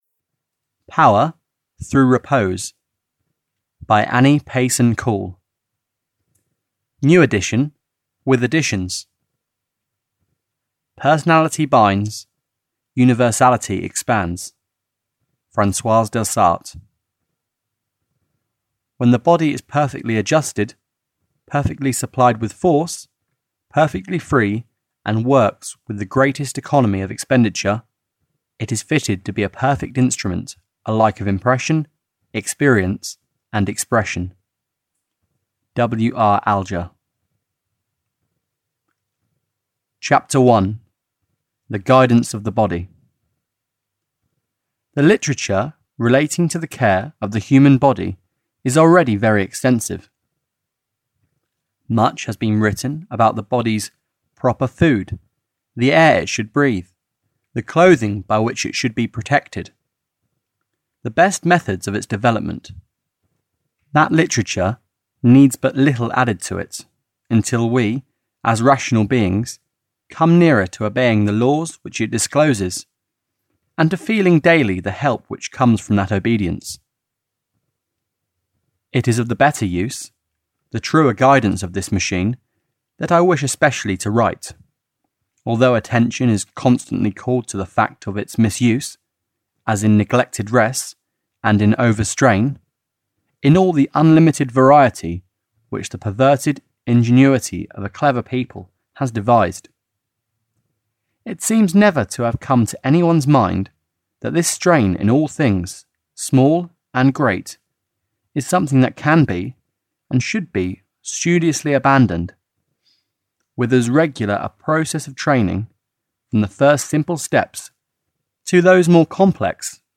Power Through Repose (EN) audiokniha
Ukázka z knihy